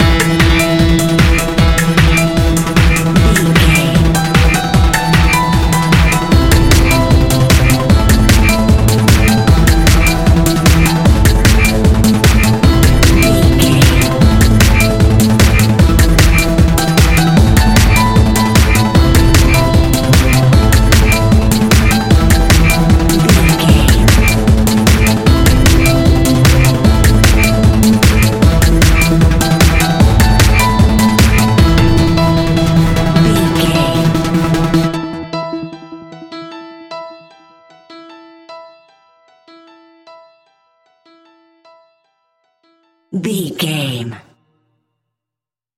Aeolian/Minor
Fast
futuristic
hypnotic
piano
drum machine
synthesiser
acid house
electronic
uptempo
synth leads
synth bass